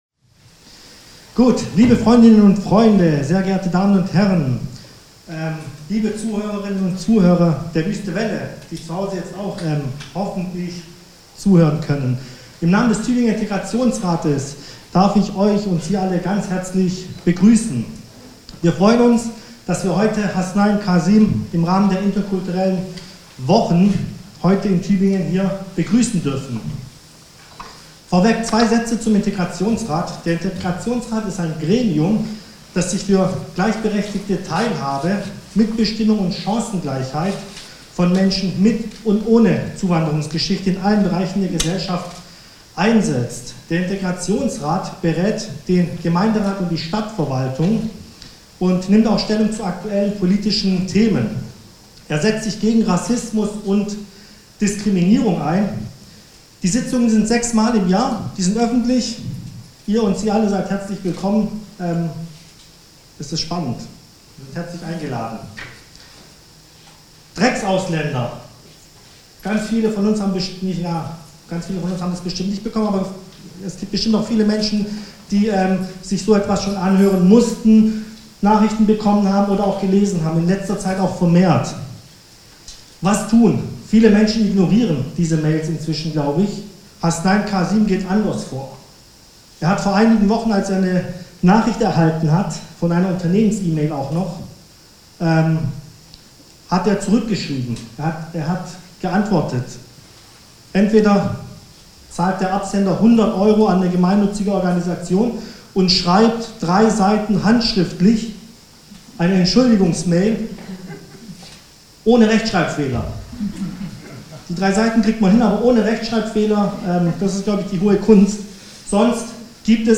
Begrüßung von Hasnain Kazim durch einen Vertreter des Integrationsrates